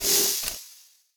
door_steam_close.wav